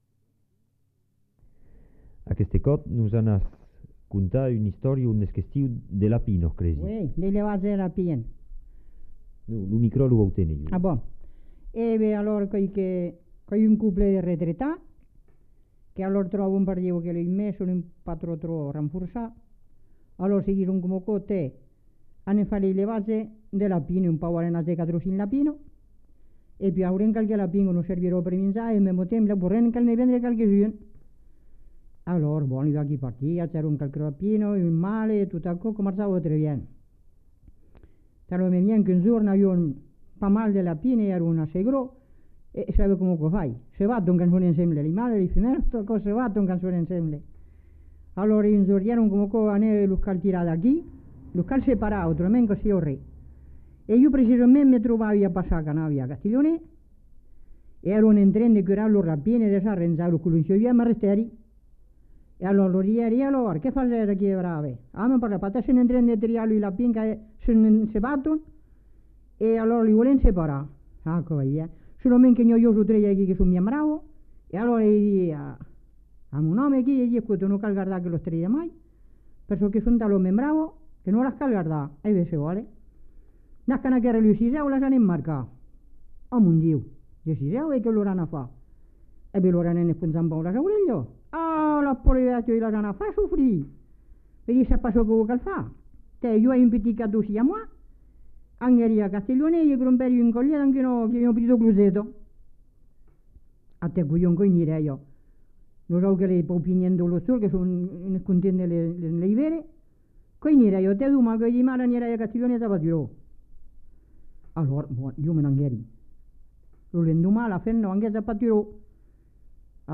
Aire culturelle : Haut-Agenais
Lieu : Villeréal
Genre : conte-légende-récit
Effectif : 1
Type de voix : voix de femme
Production du son : parlé